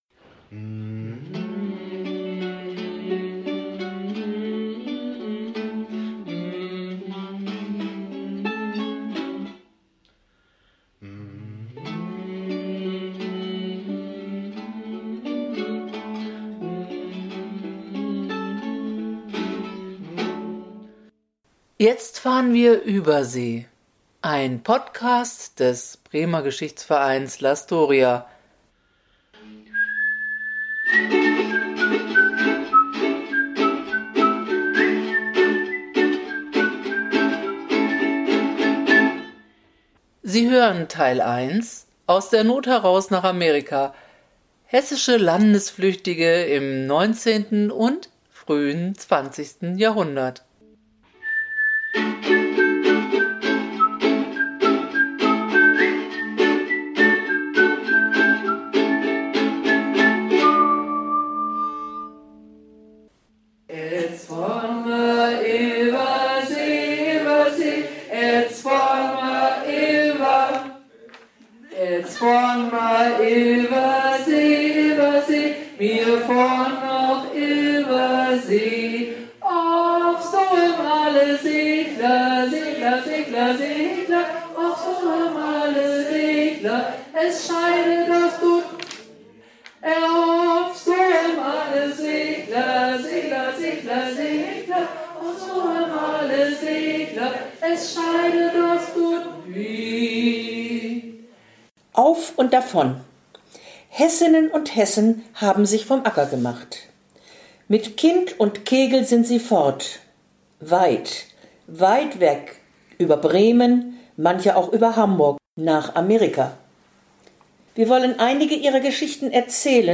Und ein kleines Stück aus einem Märchen ist enthalten, aber auch Musik und ein wenig oberhessischer Dialekt.